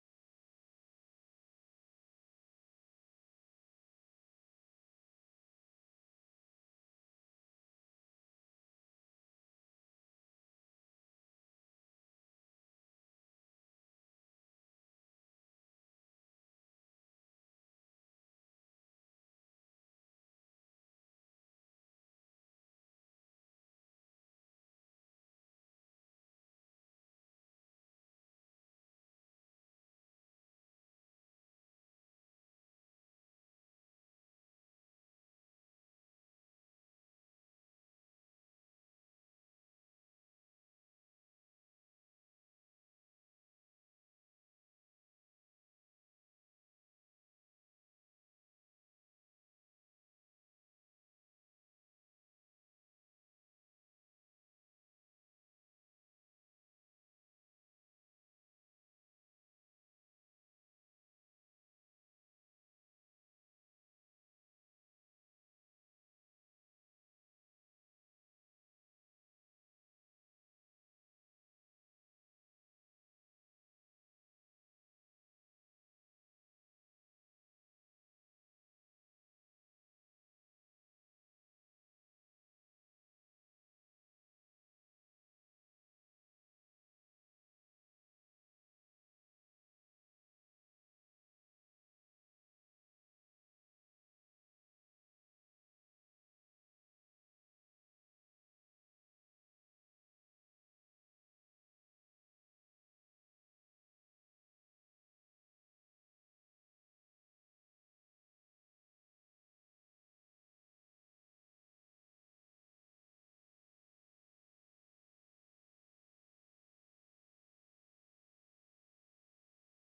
المكان: المسجد الحرام الشيخ: بدر التركي بدر التركي معالي الشيخ أ.د. بندر بليلة طه